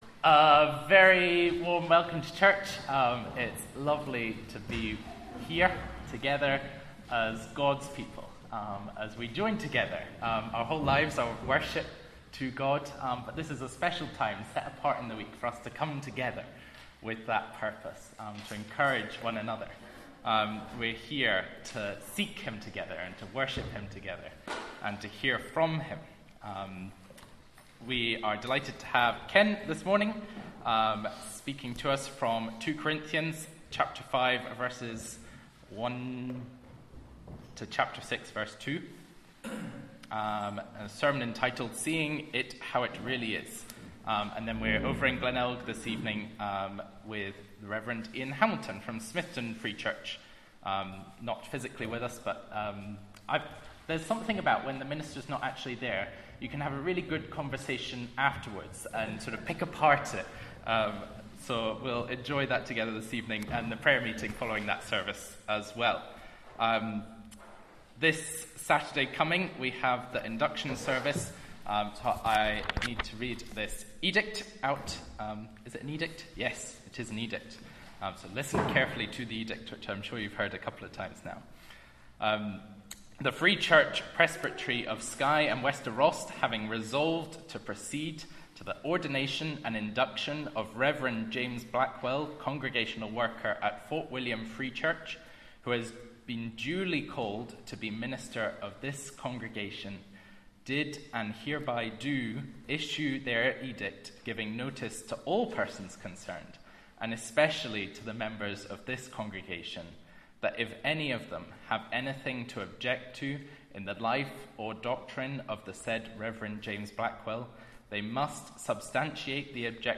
Sunday Service 18th August 2024